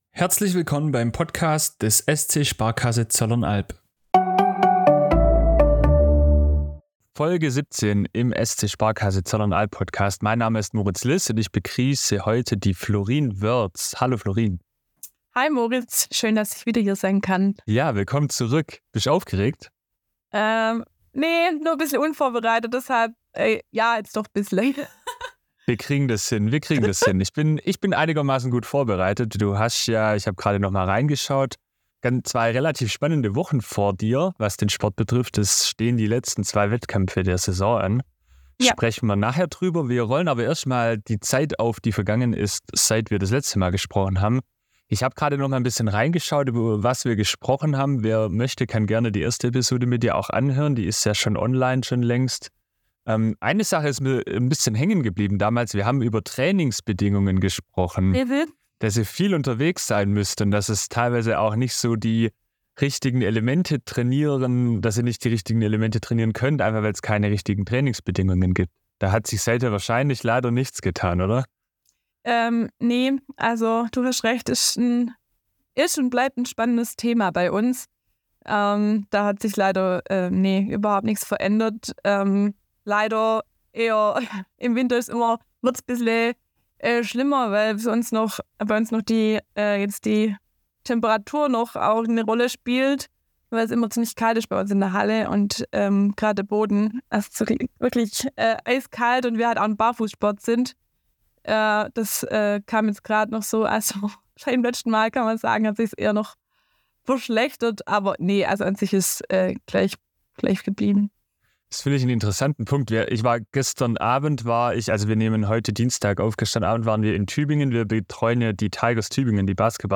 Die anfängliche Nervosität ist irgendwann auch verflogen. 11 SportlerInnen aus dem Zollernalbkreis vereint in einem Team...